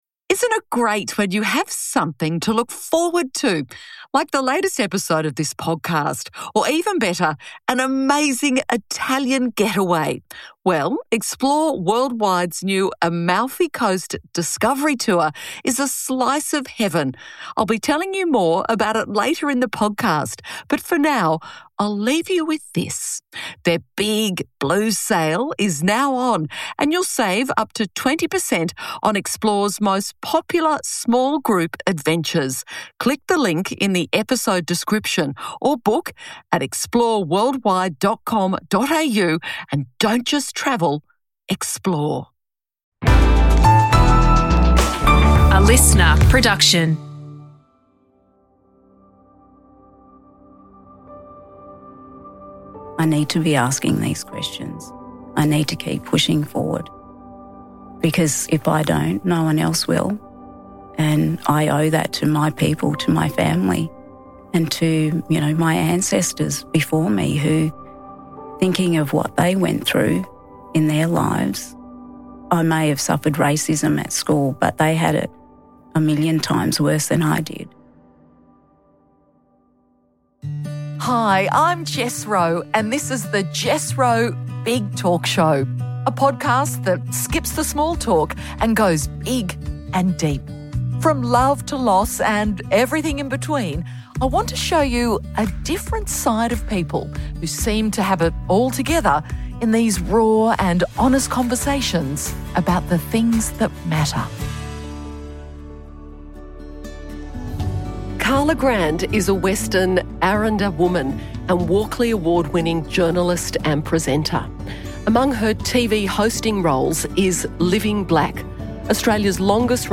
In this deeply personal conversation, Karla reflects on facing racism as a young girl to becoming one of Australia’s most respected Indigenous broadcasters. She opens up about the moments that tested her resilience, the people who shaped her path, and the drive that continues to fuel her fight for social justice. Karla shares how turning 60 has brought new joy and perspective, and why storytelling remains at the heart of who she is, not just as a journalist, but as a woman, a mother, and an advocate for truth.